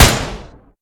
Gunshot.mp3 📥 (21.6 KB)